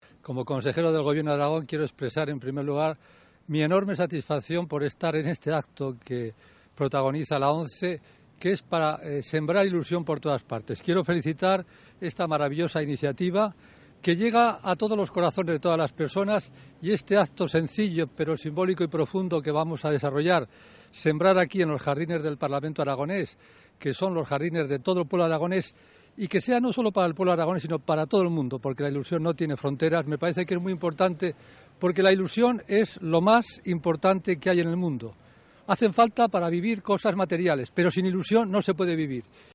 expresaba su optimismo en el acto de la plantada celebrada por los escolares aragones (archivo MP3).